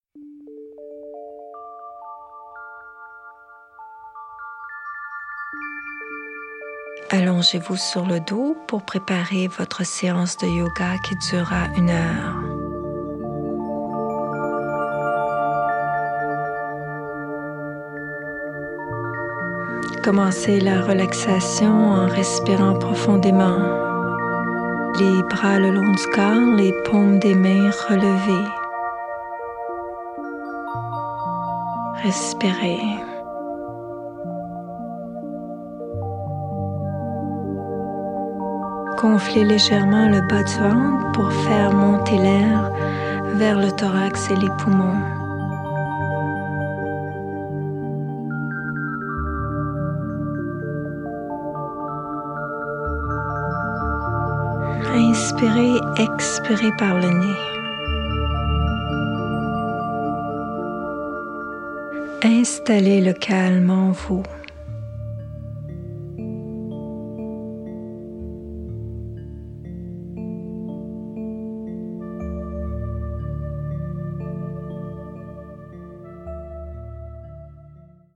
Une session guidée de yoga niveau débutant / intermédiaire avec fond musical comprenant assouplissements, asanas, pranayama, relaxation.